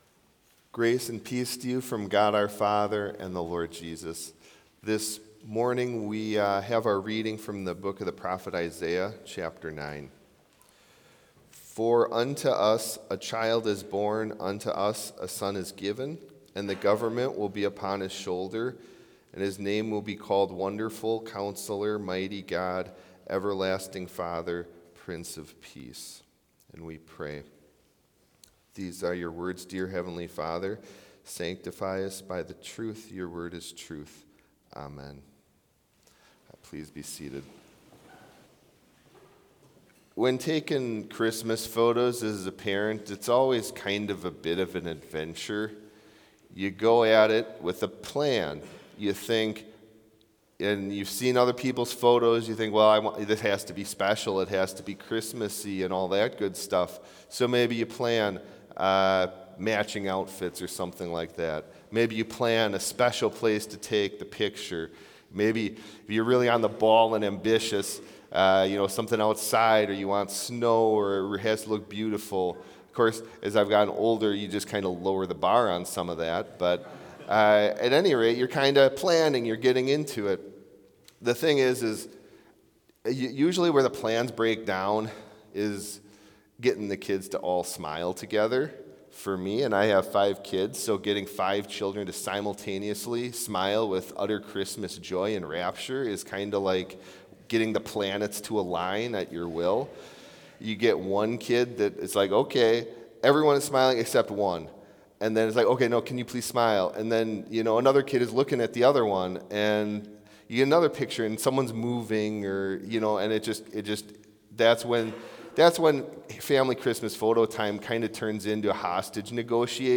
Chapel service in Bethany Lutheran College's chapel
Complete service audio for Chapel - Tuesday, December 9, 2025